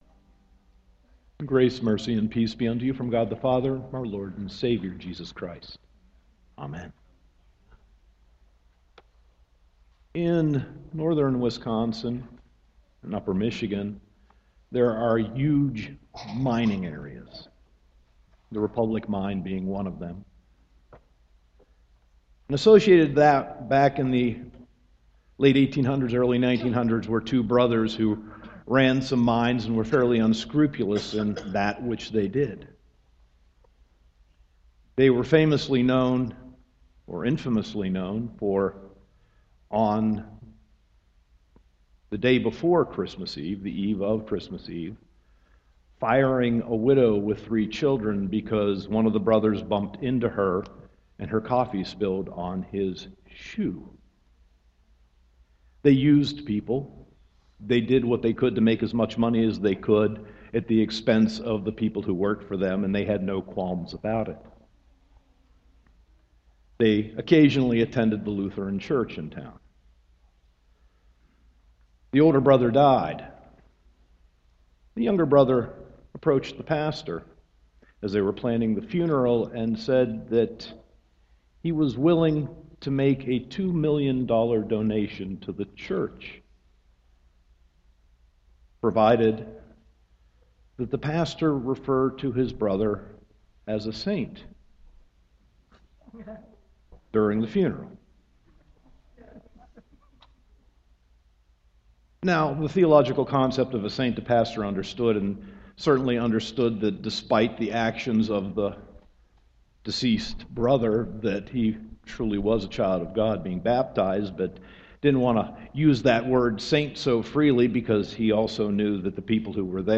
Sermon 11.2.2014